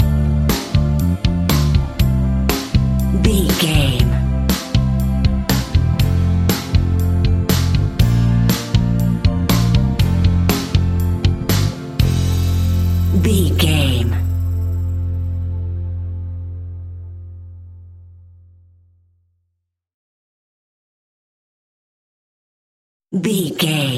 Ionian/Major
pop rock
indie pop
fun
energetic
uplifting
upbeat
groovy
guitars
bass
drums
organ